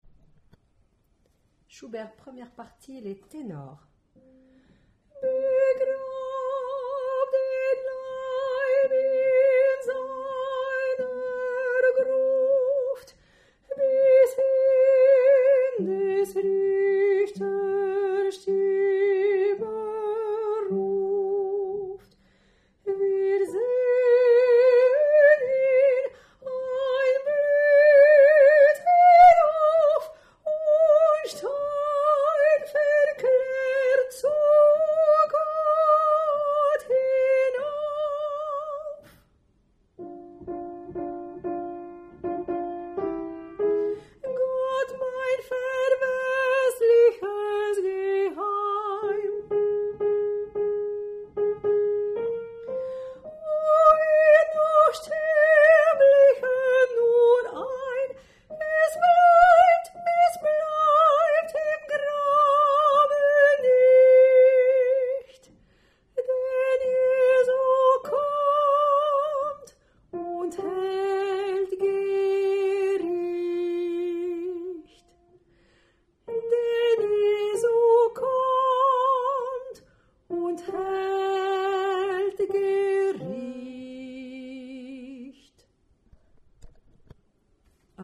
Tenor
schubert1_Tenor.mp3